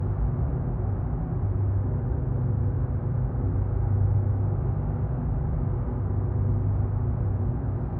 Hum Loop 4.wav